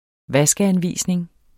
Udtale [ ˈvasgəanˌviˀsneŋ ]